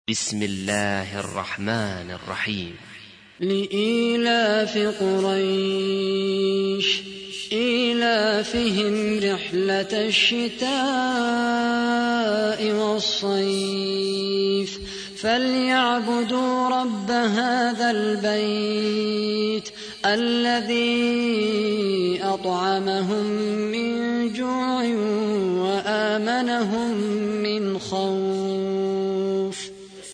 تحميل : 106. سورة قريش / القارئ خالد القحطاني / القرآن الكريم / موقع يا حسين